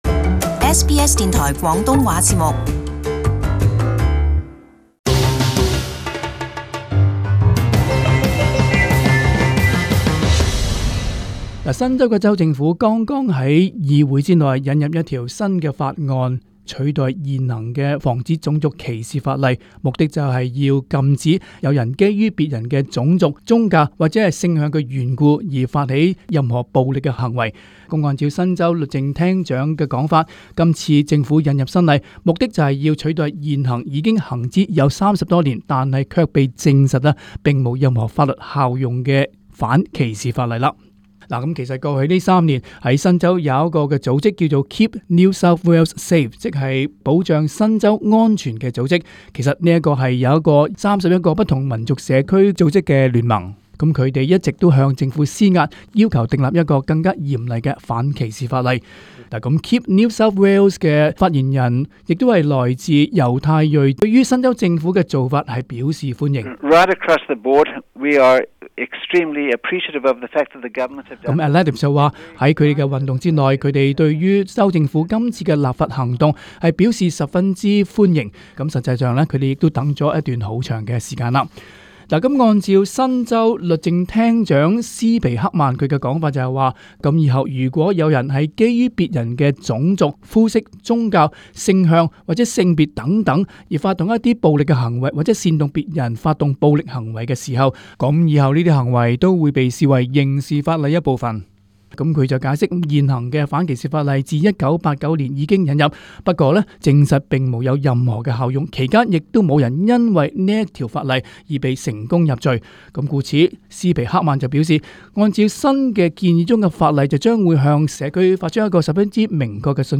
【时事报导】新州议会引入新的种族仇恨法例